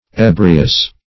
Search Result for " ebrious" : The Collaborative International Dictionary of English v.0.48: Ebrious \E"bri*ous\ ([=e]`br[i^]*[u^]s), a. [L. ebrius.]